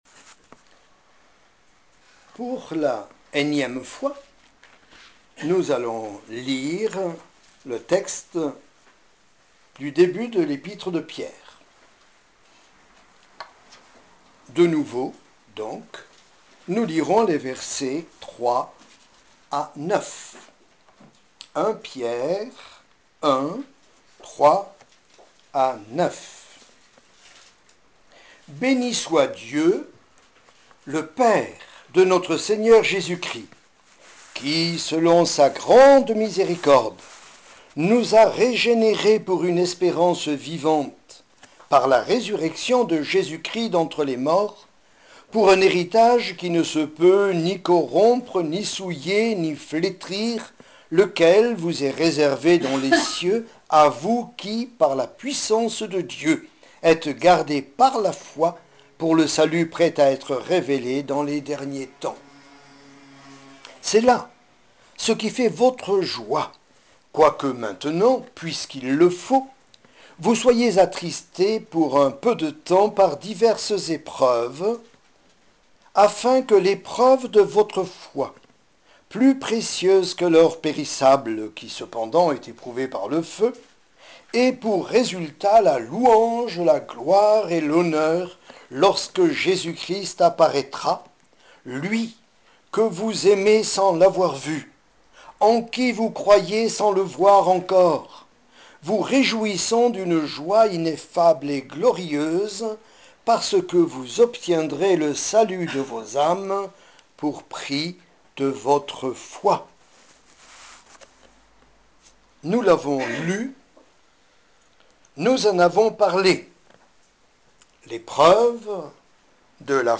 Message enregistré à l’Eglise du Creusot le 10 octobre 2010
Prédicateurs